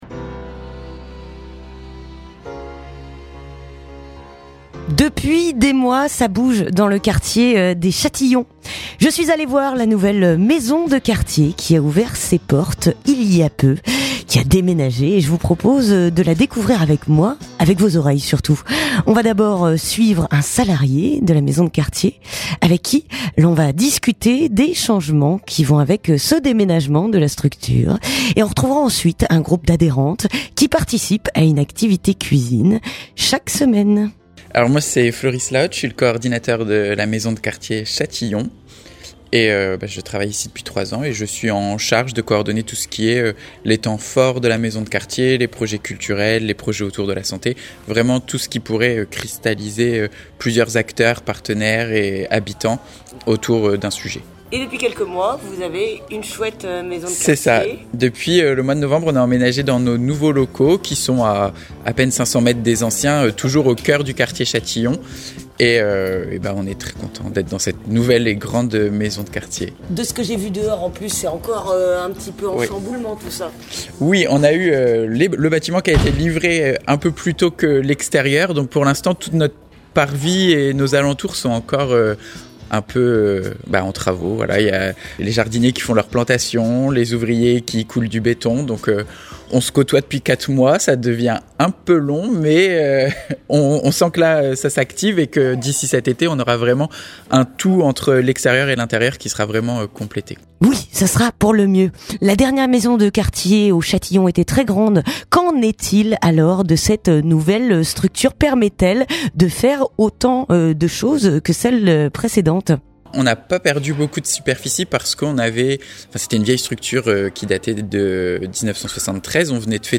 Reportage à Châtillons (16:10)